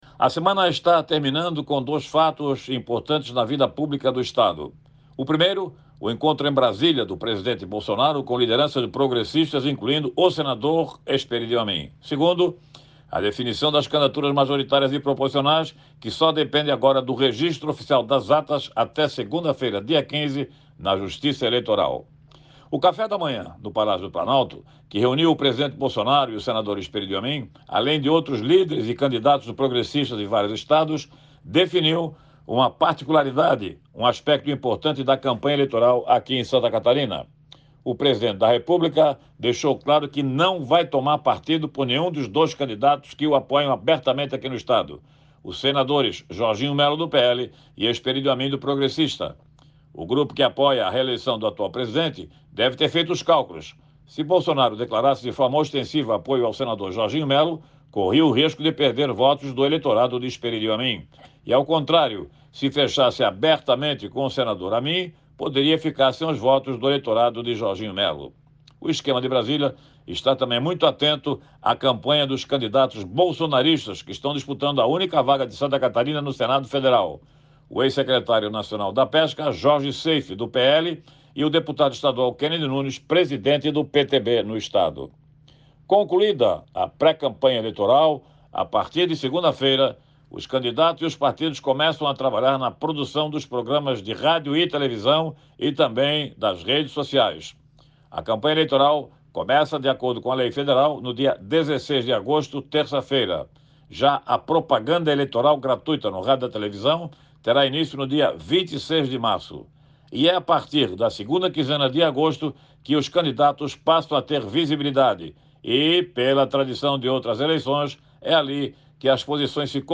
O jornalista destaca começo da campanha eleitoral com programas de rádio e televisão em 26 de agosto